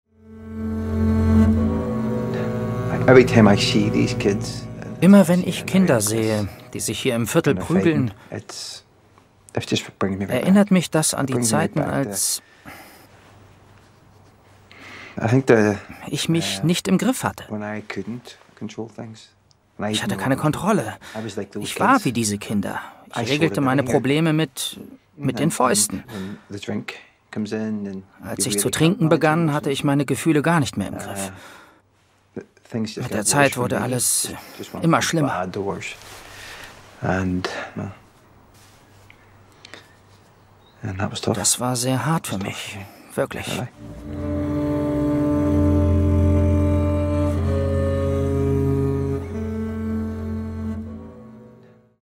Rollen - Hörspiel + Game
Audio Drama (Hörspiel), Game, Lip-Sync (Synchron)